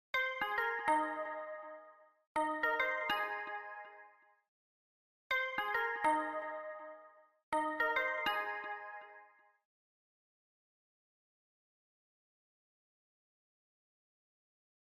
Brisbane Metro Arrival Sound